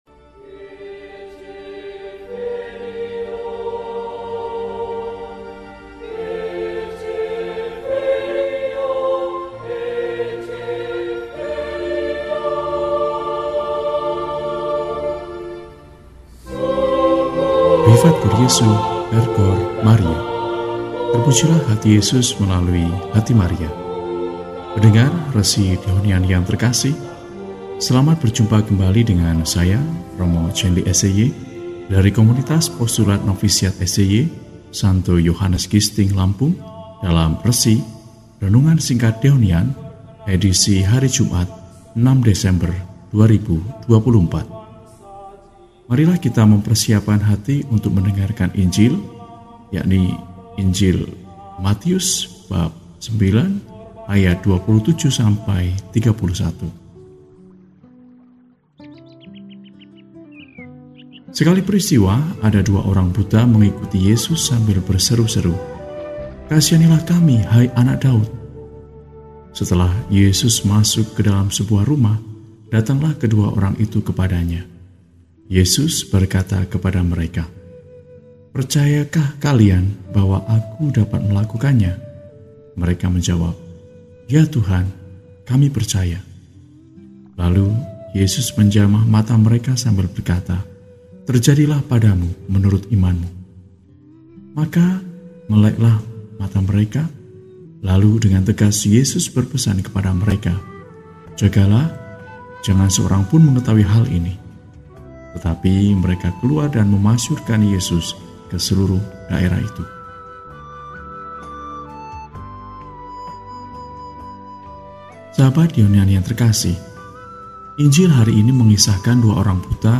Jumat, 06 Desember 2024 – Hari Biasa Pekan I Adven – RESI (Renungan Singkat) DEHONIAN